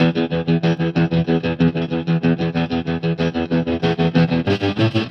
Trem Trance Guitar 01d.wav